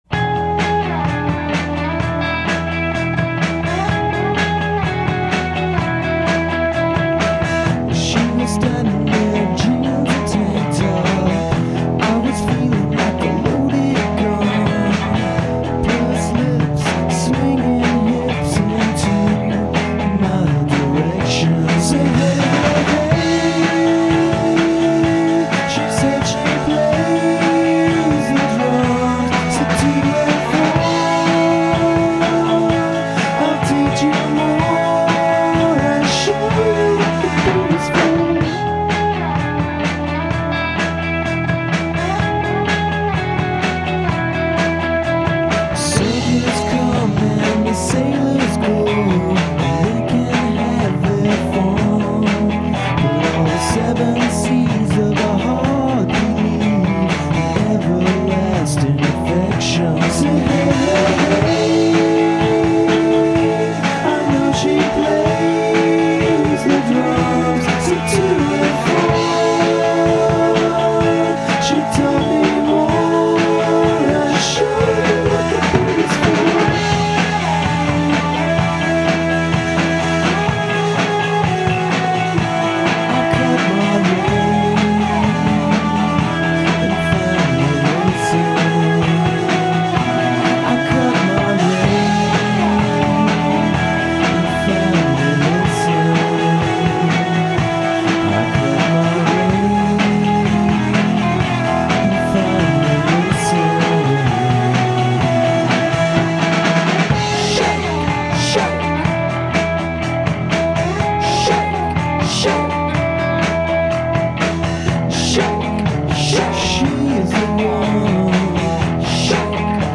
Crappy Rehearsal Tape mp3:
this Bunnymen-inspired jam has a new